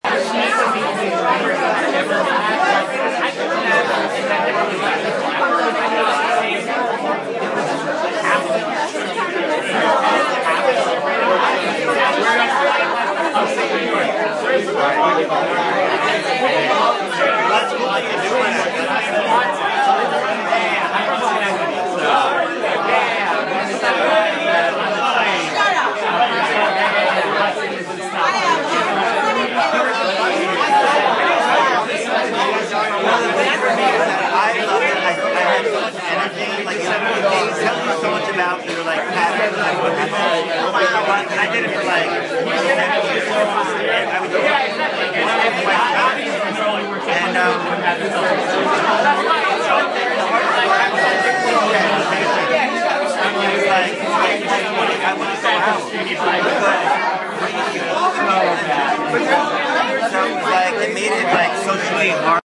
Download Party sound effect for free.